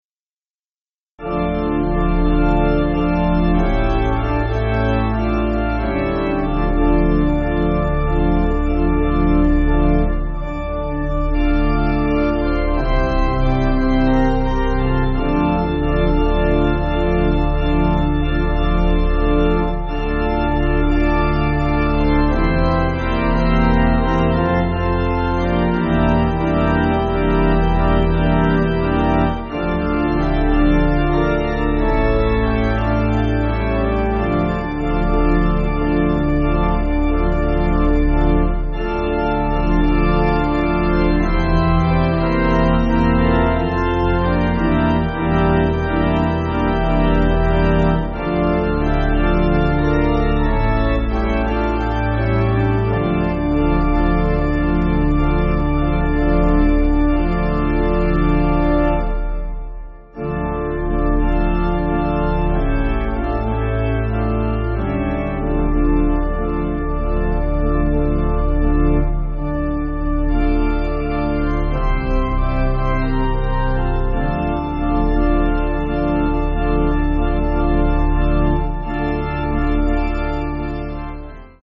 Organ
(CM)   5/Dm
7/8 Time